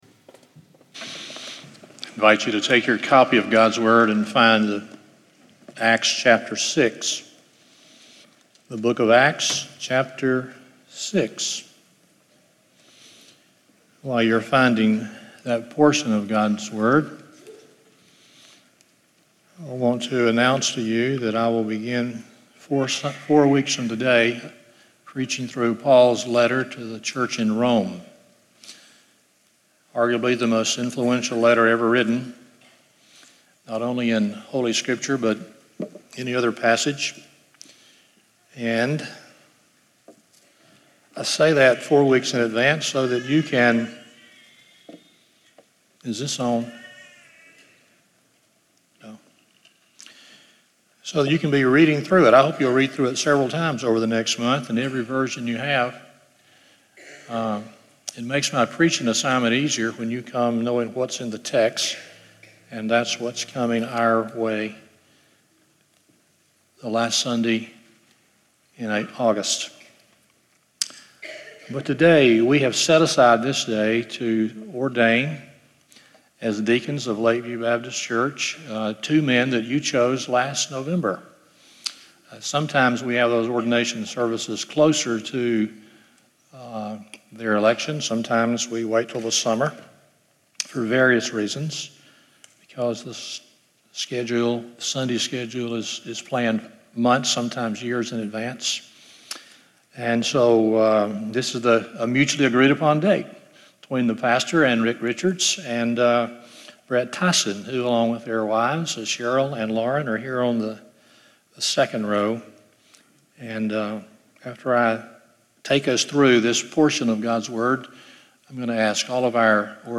Stand Alone Sermons Passage: Acts 6:1-7 Service Type: Sunday Morning Pastors are servant leaders